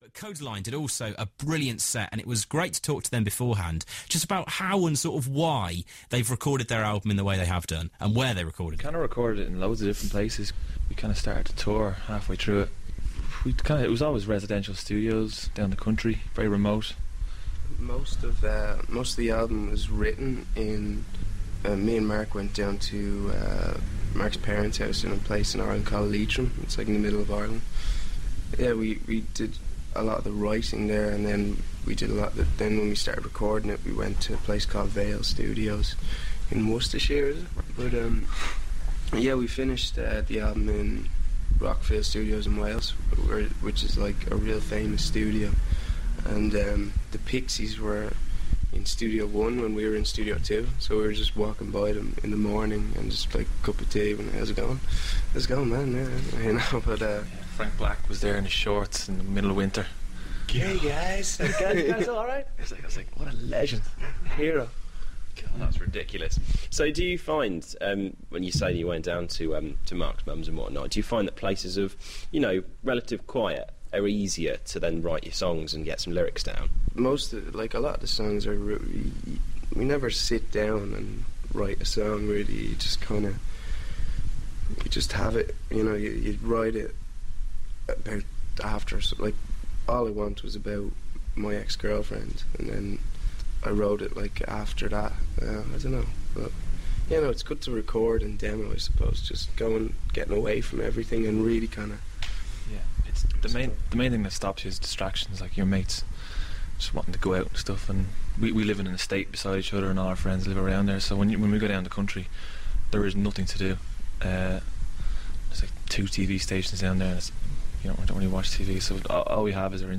Mar13 - Kodaline Interview